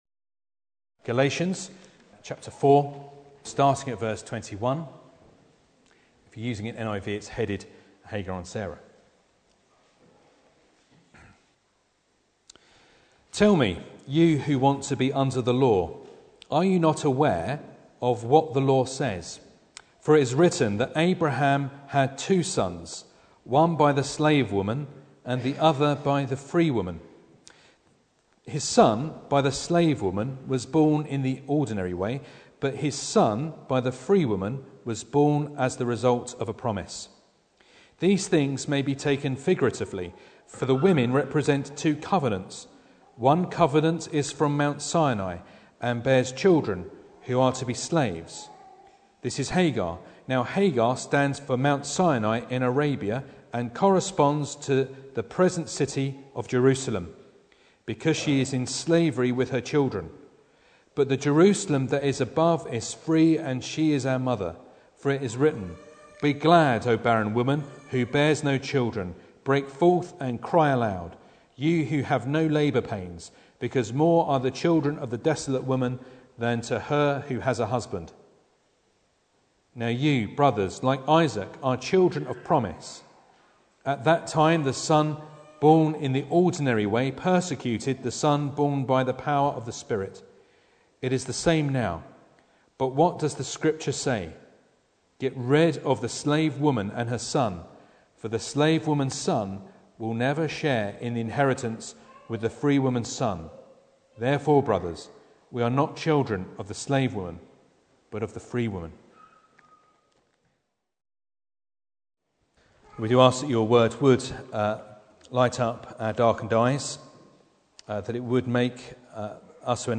Galatians 4:21-31 Service Type: Sunday Morning Bible Text